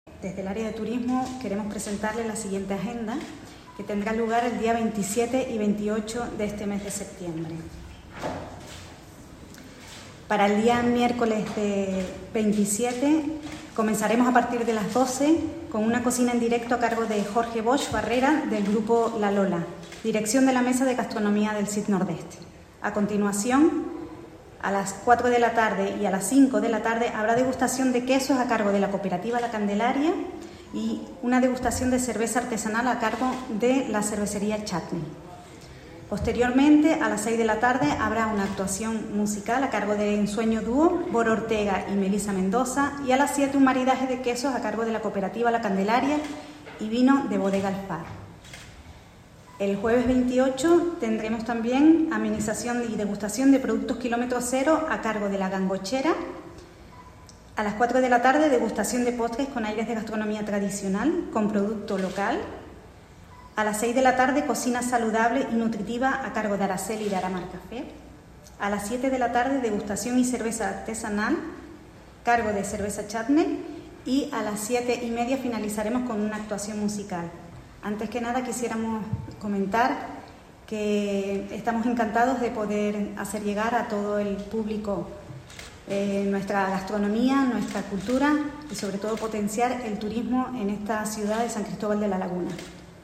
El acto de presentación de esta agenda cultural y de ocio tuvo lugar este lunes, 25 de septiembre, en la Casa de los Capitanes y contó con la participación del alcalde lagunero, Luis Yeray Gutiérrez, y la concejala responsable del Área, Leticia Villegas.
Presentación Agenda Día Mundial del Turismo por la Concejala Leticia Villegas